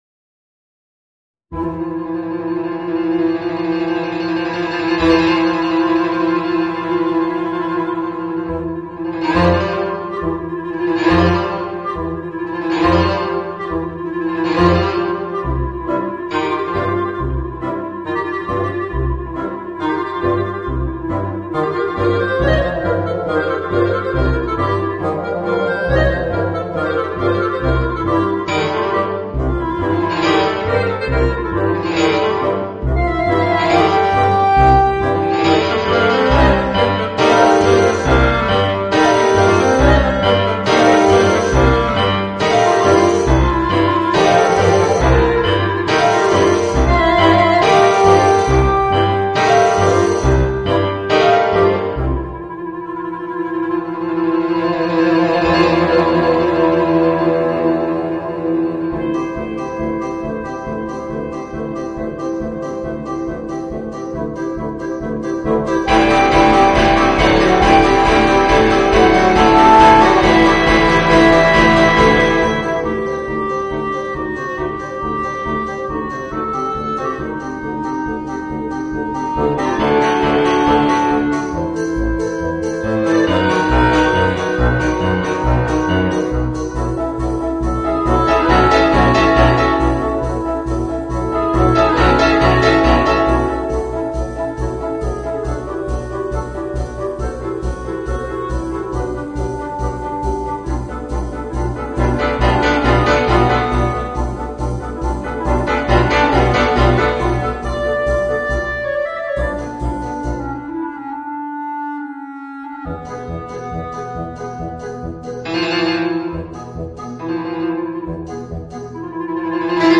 Voicing: Woodwind Quintet and Rhythm Section